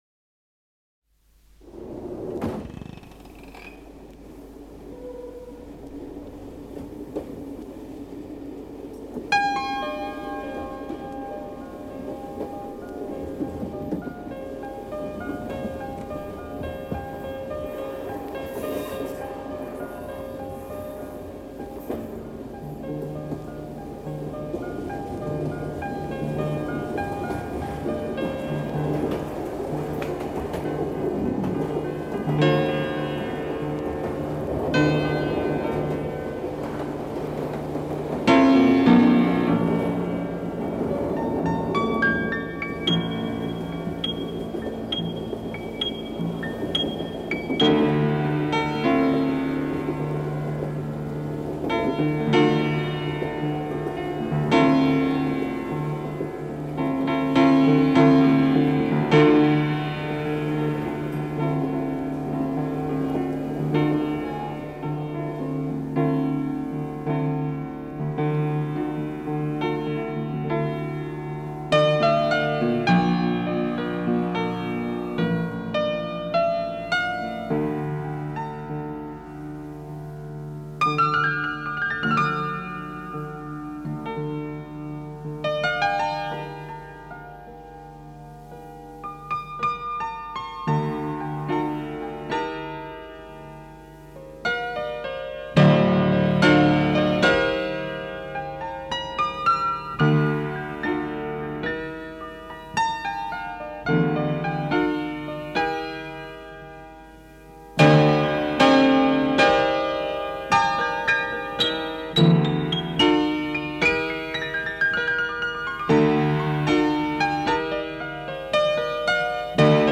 Feurich Piano in der Stavenstraße im Bremer Schnoorviertel
Allerdings ist der Klang unglaublich für die Größe des Instruments.
Eines davon trägt den passenden Namen „Personal Voyage“ Ich habe damals viel mit Tonbandeinspielungen und Sounds gearbeitet, in diesem Fall sind es die Geräusche der deutschen Bundesbahn. Das Stück ist komplett frei improvisiert worden.